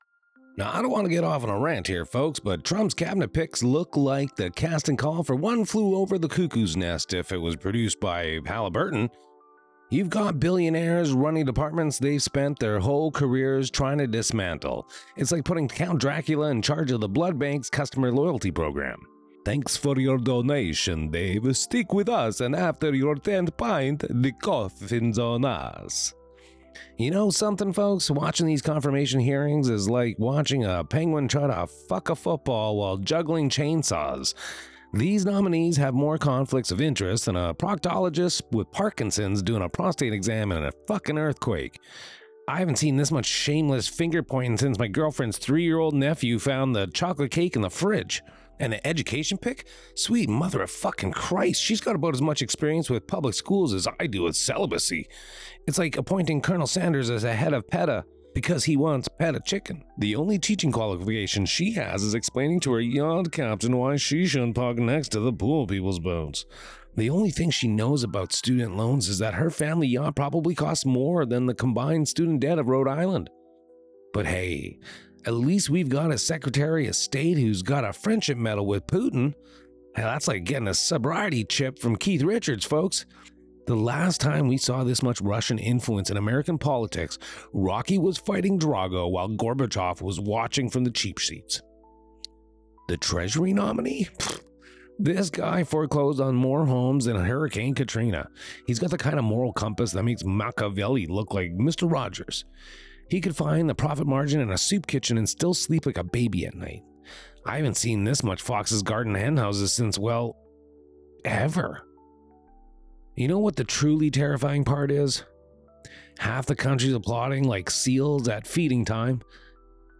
Why This Rating: Four peppers for political napalm disguised as comedy.
The F-bombs are censored just enough to keep the pearl-clutchers from fainting.
007-RANT.mp3